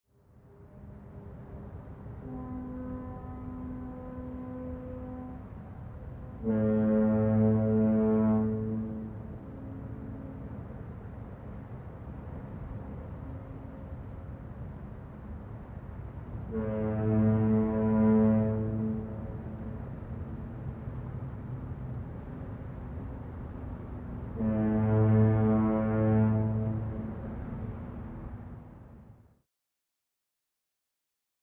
Foghorns ( Golden Gate Bridge ), Distant W Light City And Traffic Background.